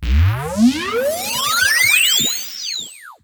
SFX SwaggedOut 5.wav